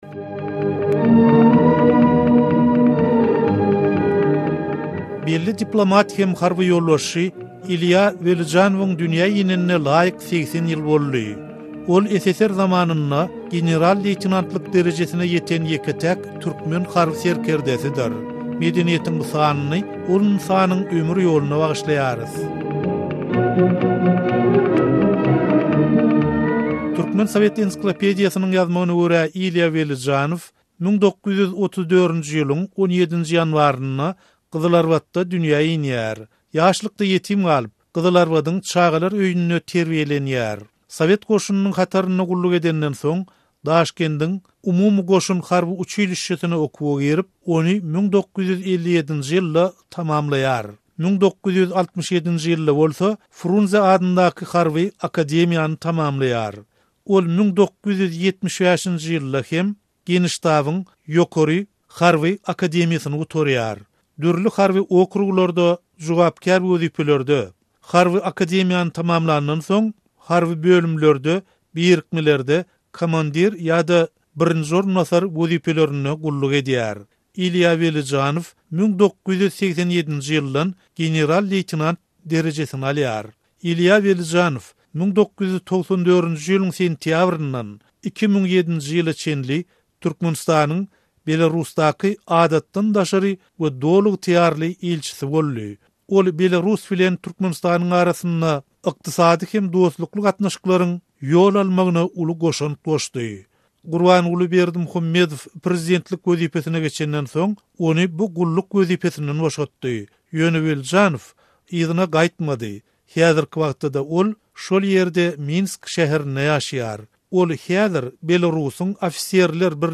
goşgulary öz sesi bilen berilýär.